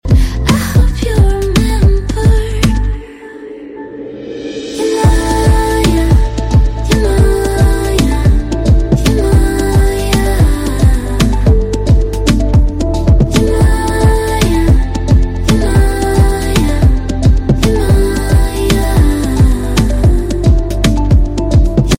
chill to this calming song